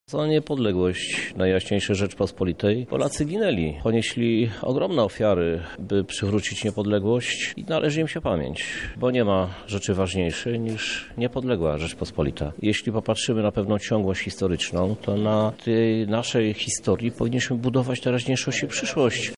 Jak podkreśla wojewoda lubelski Przemysław Czarnek, wolność jest najważniejsza.
Tegoroczne obchody rozpoczęła uroczysta sesja Rady Miasta Lublin w Trybunale Koronnym.